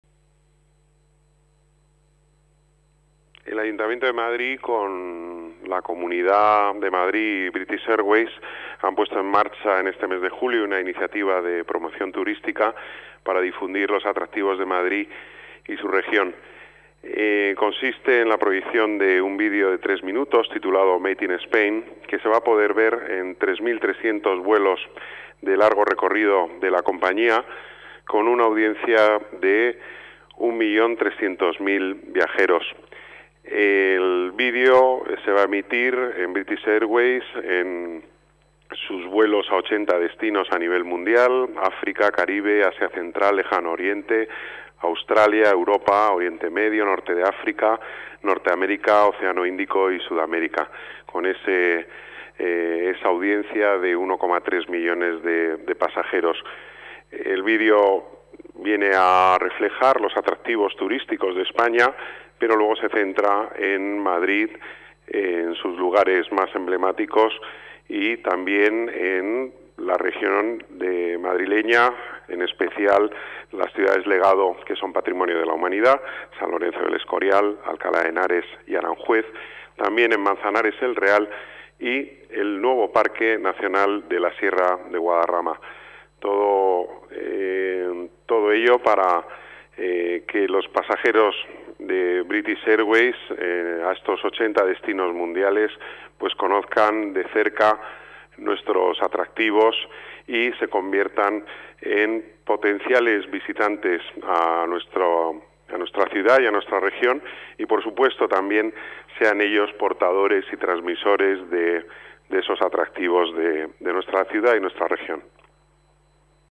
Nueva ventana:Declaraciones de Pedro Corral, delegado de Las Artes, Deportes y Turismo